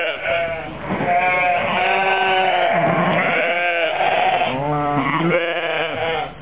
1 channel
sheep.mp3